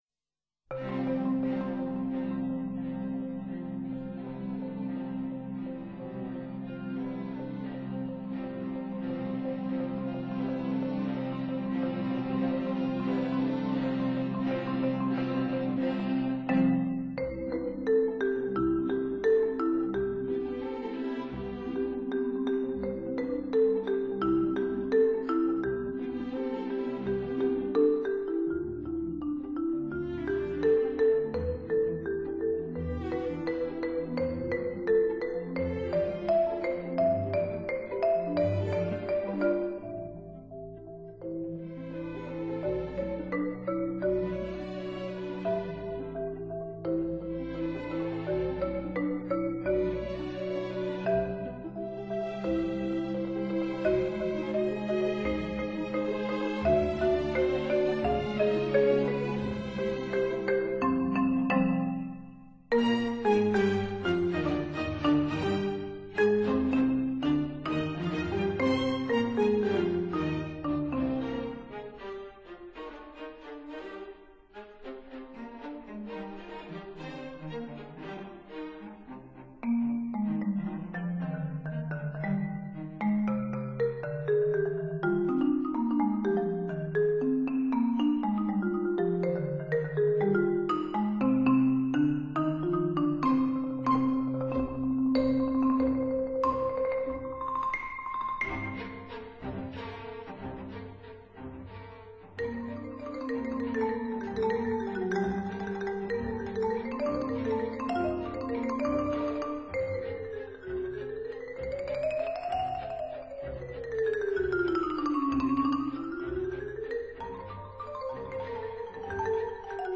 打擊樂協奏曲輯
蘇格蘭的打擊樂演奏家。